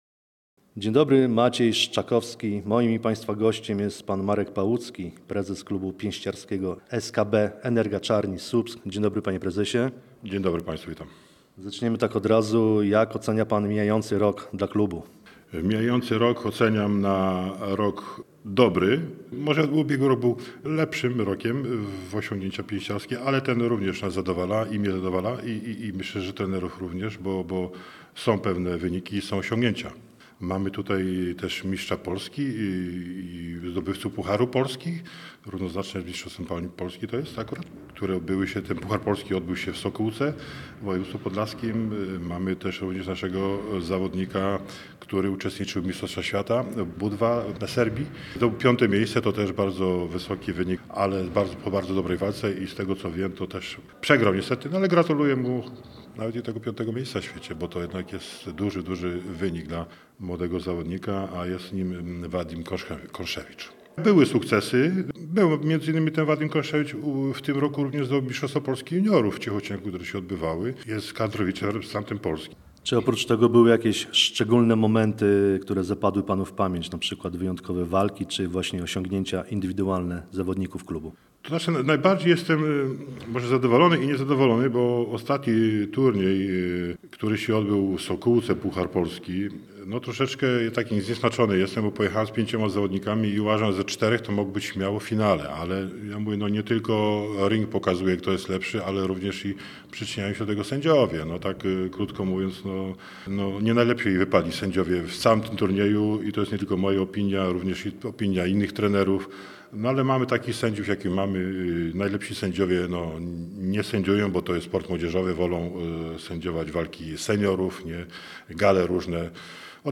Nasz reporter rozmawiał także z pięściarzami z klubu, którzy podzielili się swoimi pasjami, sukcesami i marzeniami związanymi z boksem.